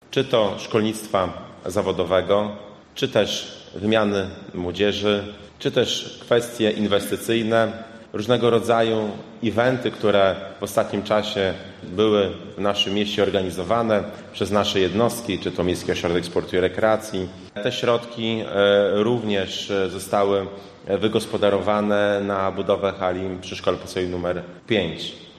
W łomżyńskiej Hali Kultury odbyła się konferencja prasowa podsumowująca projekt „Łomża – Miasto, w którym żyję i pracuję”.
Środki zostały wykorzystane na wielu płaszczyznach – mówił prezydent Mariusz Chrzanowski: